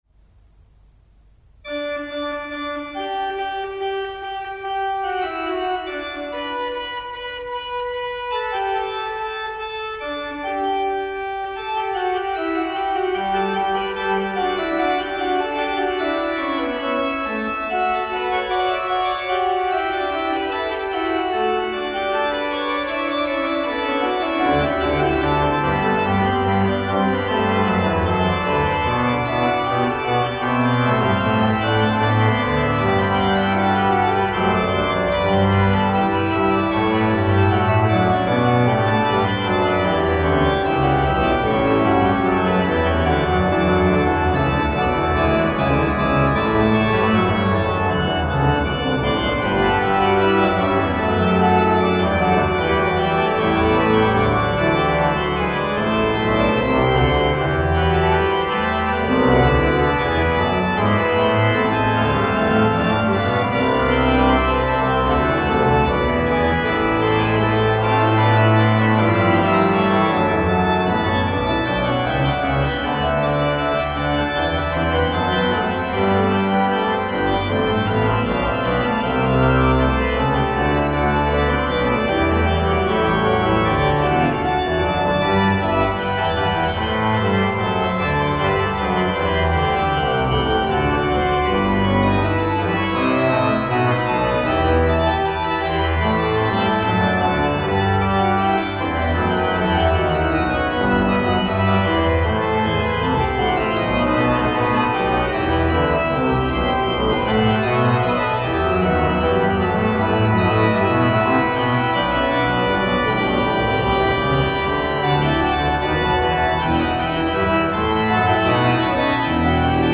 Präludium und Fuge in G-Dur BWV 541